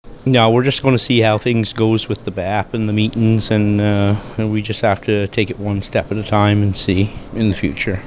Mayor Gordon Burke says the council is sticking to the public review process: